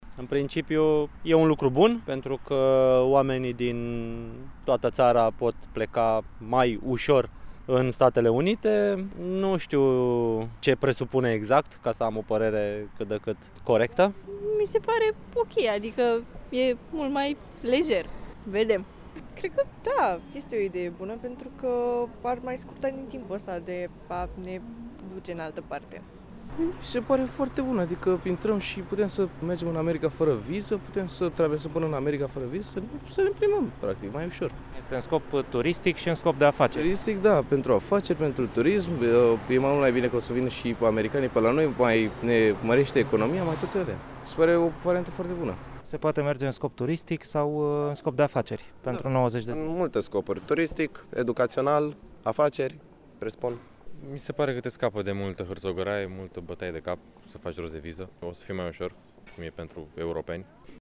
Ce părere au constănțenii: